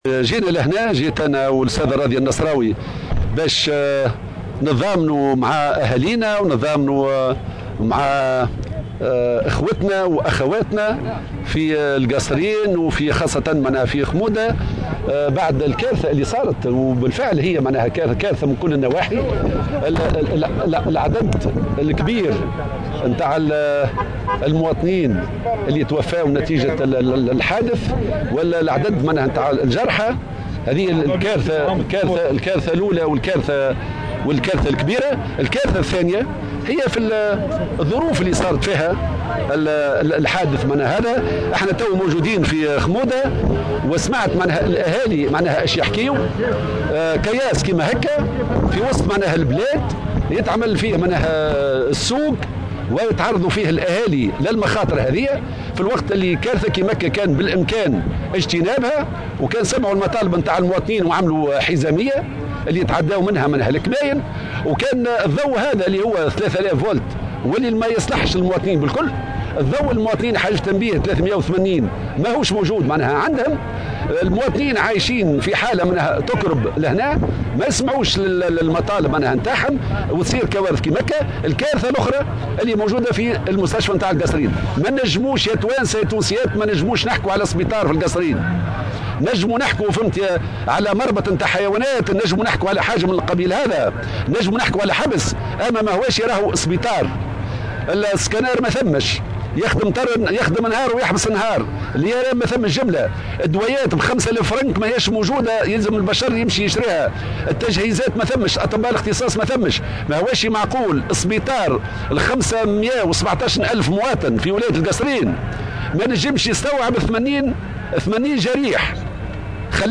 وتحدث الهمامي، في تصريح للجوهرة أف أم، عن "كارثتين إضافيتين"، إلى جانب كارثة حادث المرور، تتمثلان في الوضع التنموي في منطقة خمودة، والوضعية المزرية للمستشفى الجهوي بالقصرين والذي وصفه بـ"إسطبل الحيوانات، أو السجن" لافتقاره لأبرز المقومات الكفيلة بتقديم الخدمات الأساسية للمواطنين، من جهاز "سكانار" وجهاز IRM، وعدم وجود أطباء اختصاص، وهي عوامل جعلته غير قادر على استقبال كافة الجرحى في الحادث.